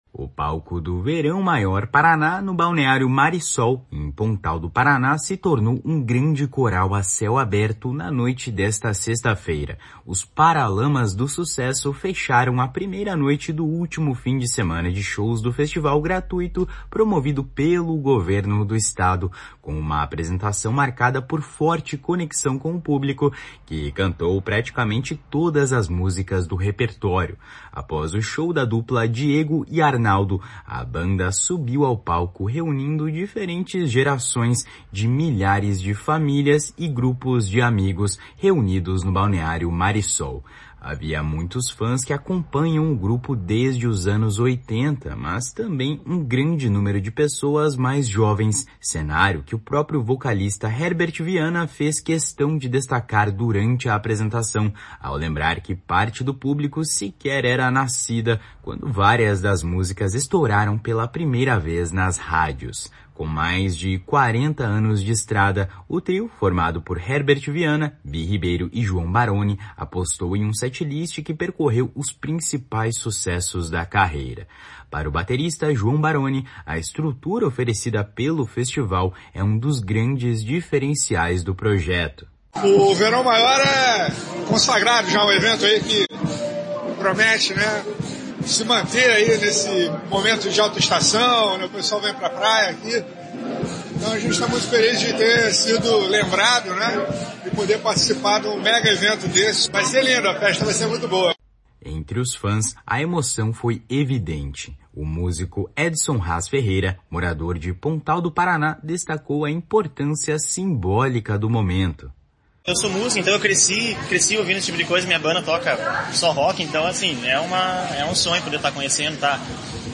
Para o baterista João Barone, a estrutura oferecida pelo festival é um dos grandes diferenciais do projeto.